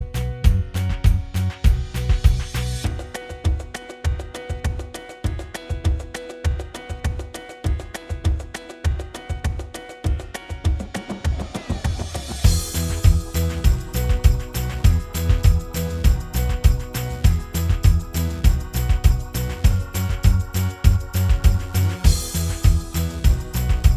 Minus All Guitars Pop (2010s) 3:18 Buy £1.50